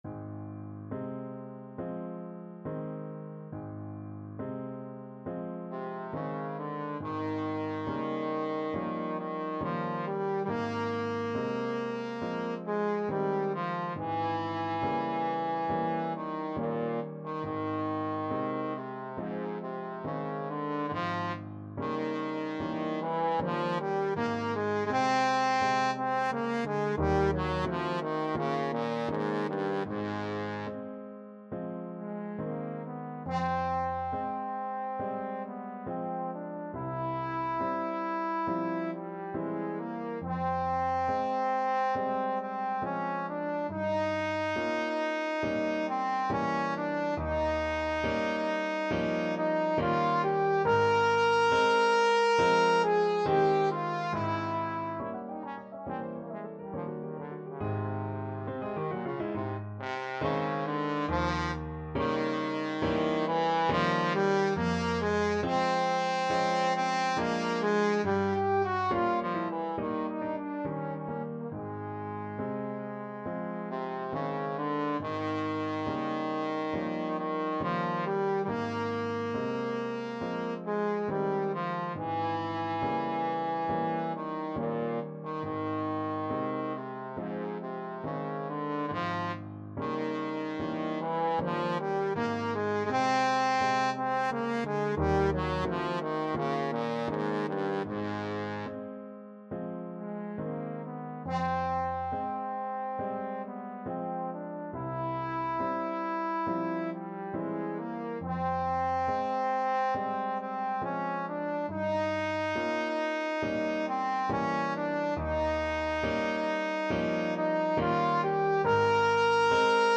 Classical Clara Schumann Piano Concerto in Am (Op.7) Second Movement Main Theme Trombone version
Ab major (Sounding Pitch) (View more Ab major Music for Trombone )
4/4 (View more 4/4 Music)
Andante non troppo con grazia =69
Classical (View more Classical Trombone Music)
c_schumann_pno_concerto_am_2nd_mvt_TBNE.mp3